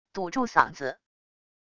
堵住嗓子wav音频